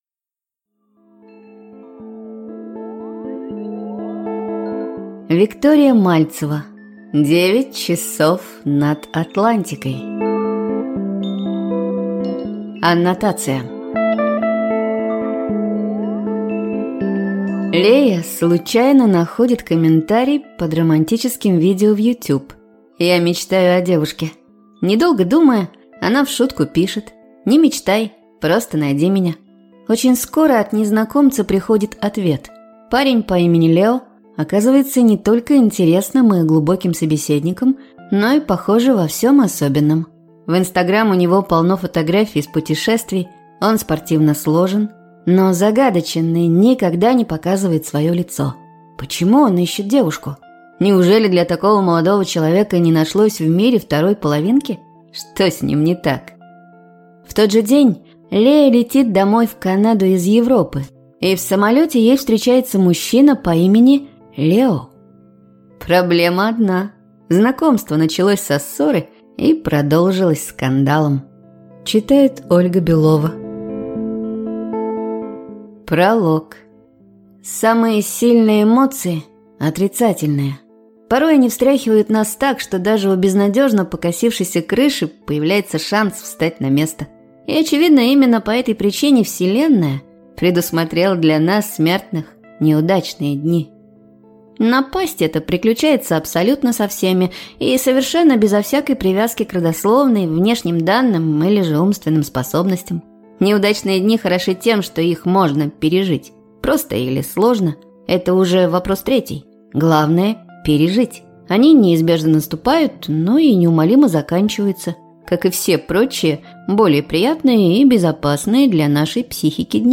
Аудиокнига 9 часов над Атлантикой | Библиотека аудиокниг